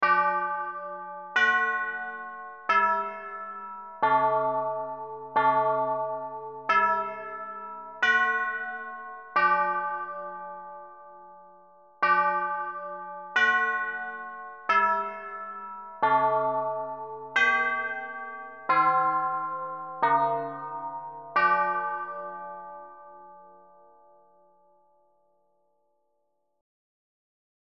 trust bells.mp3